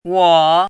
chinese-voice - 汉字语音库
wo3.mp3